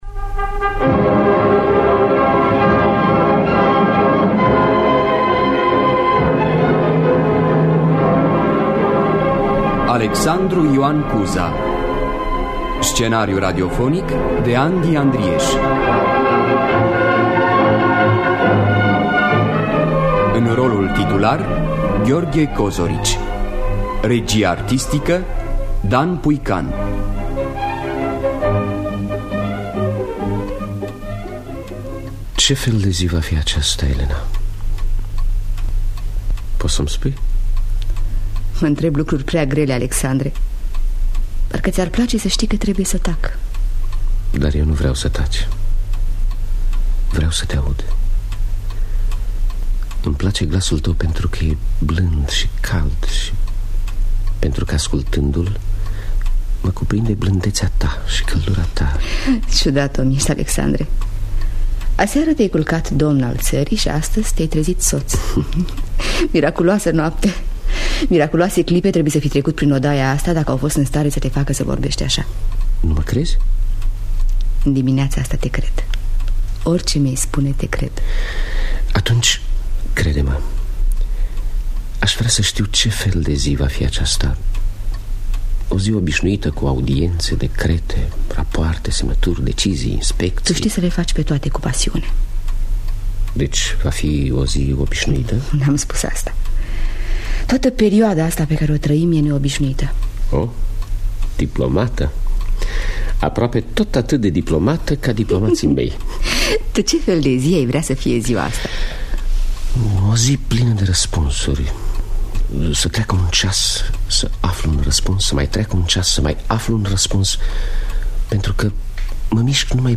– Teatru Radiofonic Online